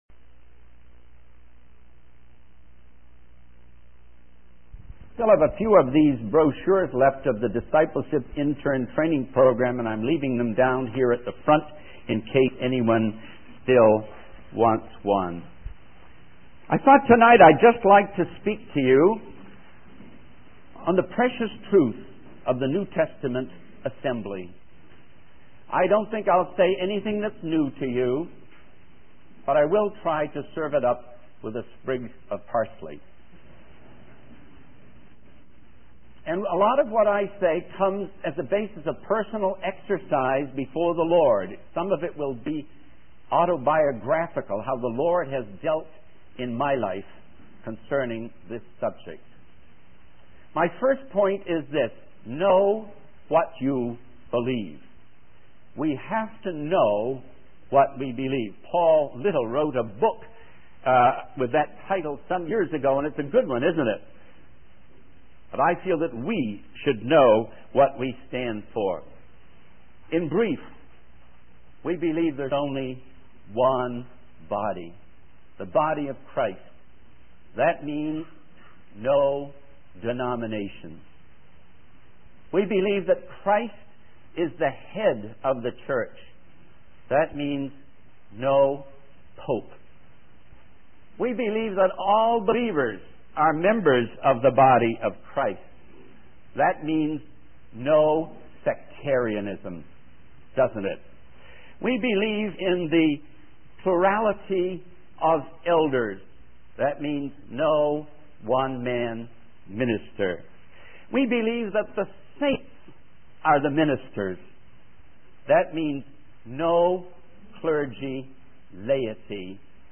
In this sermon, the speaker focuses on Ephesians chapter 4, specifically verses 7-10. The passage talks about the grace given to each believer according to the gift of Christ.